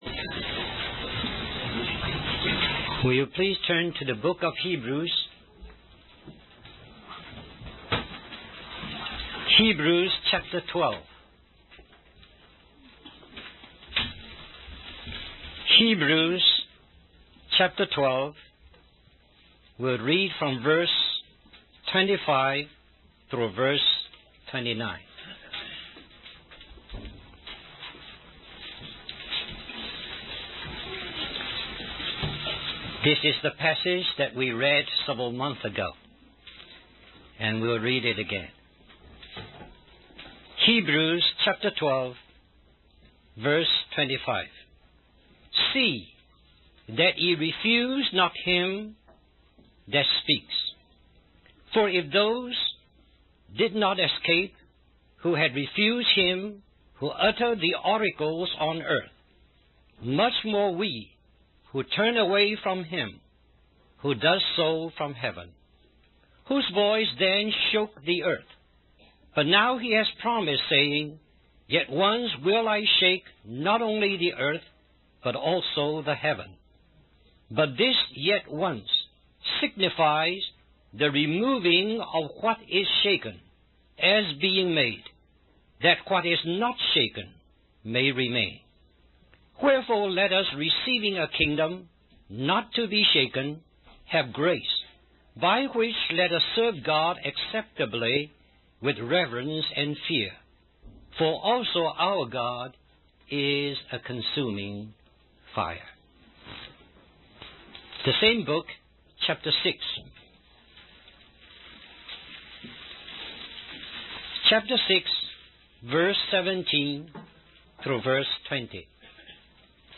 In this sermon, the speaker emphasizes the role of priests in serving both God and the world.